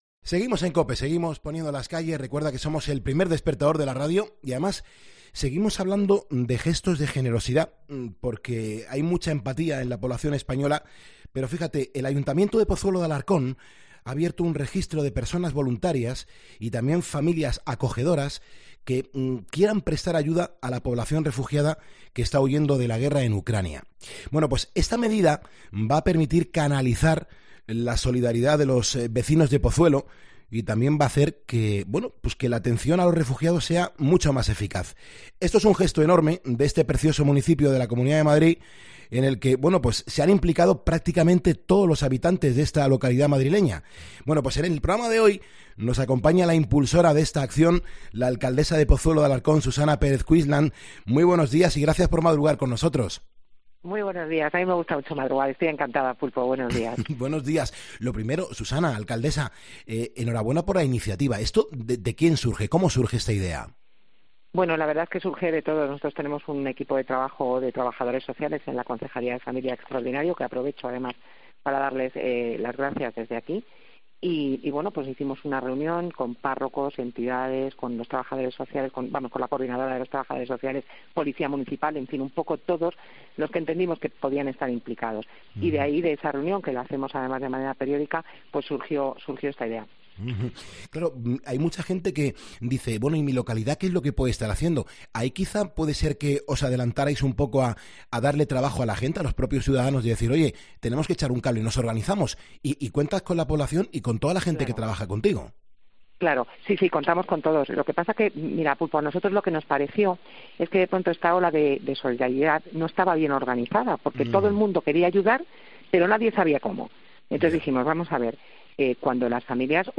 En ‘Poniendo las Calles’ hemos hablado con la impulsora de este proyecto, la alcaldesa del municipio de Pozuelo de Alarcón Susana Pérez Quislant para hablarnos de este proyecto.